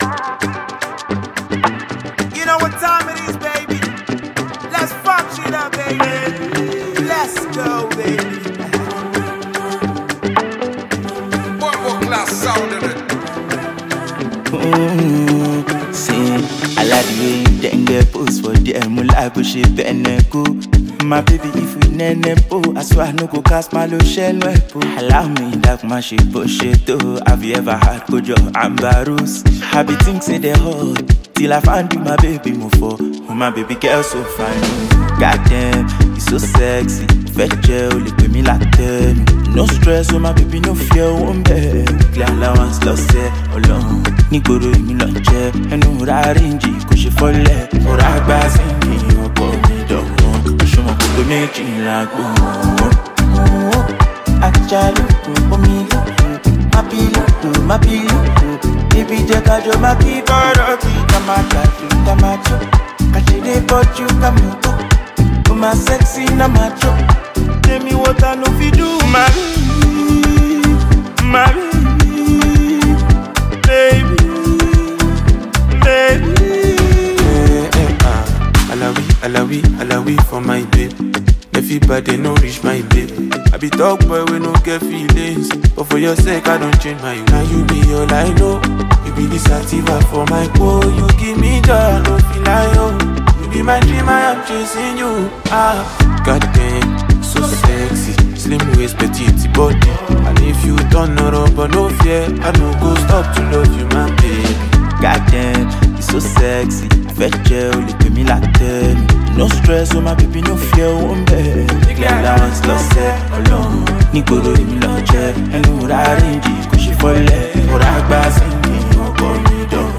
Talented Nigerian singer and songwriter